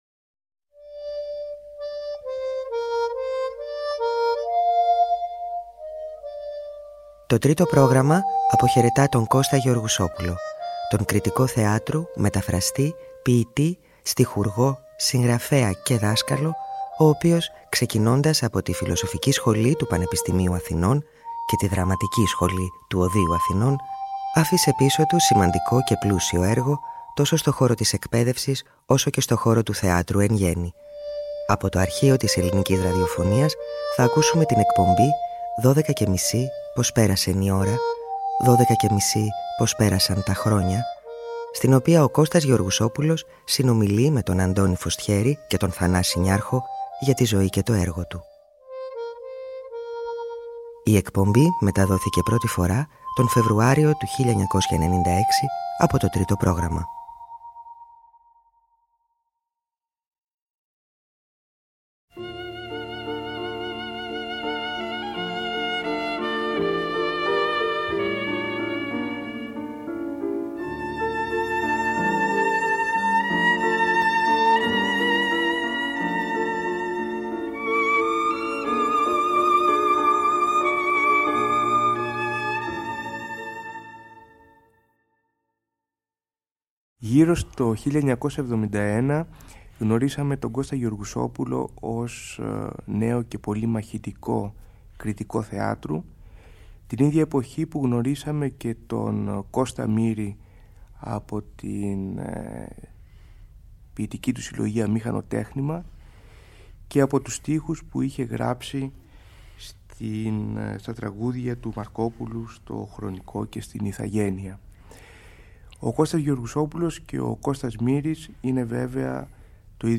Το Τρίτο Πρόγραμμα τιμά τη μνήμη του εμβληματικού διανοούμενου που έφυγε από τη ζωή πριν από λίγες ημέρες σε ηλικία 87 ετών μεταδίδοντας επιλεγμένες εκπομπές από το ανεξάντλητο αρχείο του.
Ο Κώστας Γεωργουσόπουλος συνομιλεί με τον Αντώνη Φωστιέρη και τον Θανάση Νιάρχο για τη ζωή και το έργο του.